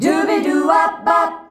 DUBIDUWA E.wav